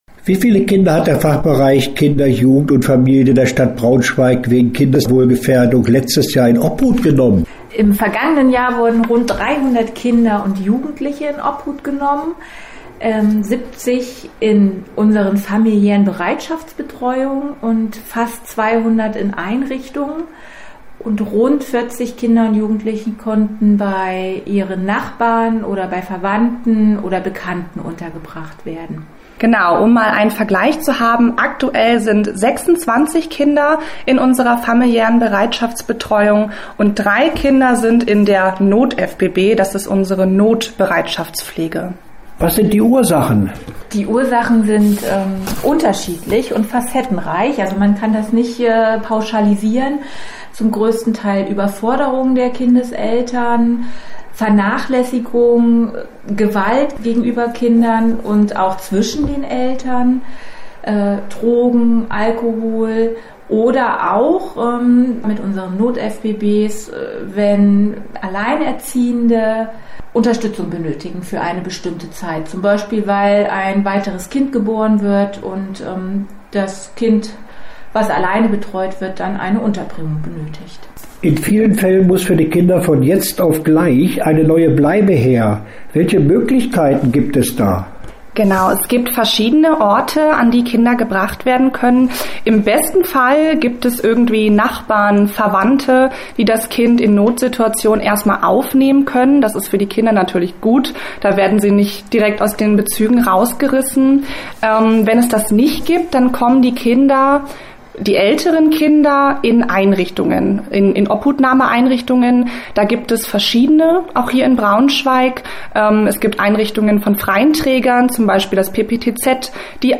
Interview-Pflegefamilien_wa.mp3